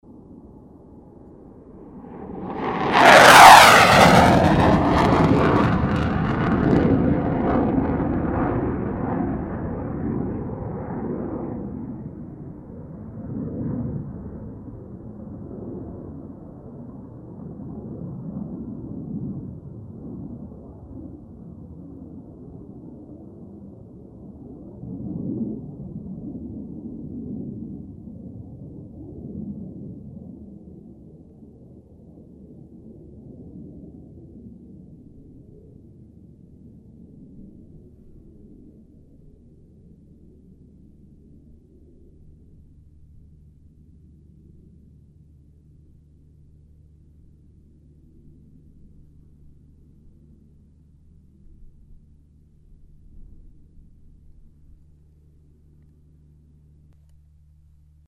bruit uchaux
BRUIT AVION DE CHASSE
PassageRafale.mp3